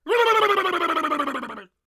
LAUGH14.wav